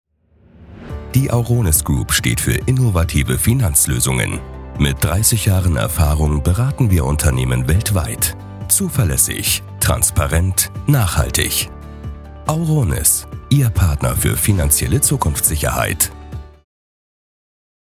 • Eigenes Tonstudio
Imagefilm - seriös sachlich
Imagefilm-serioes-sachlich.mp3